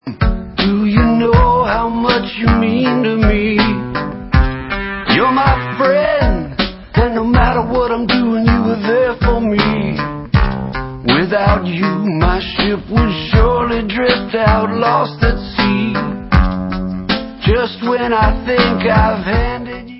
americká indie-rocková kapela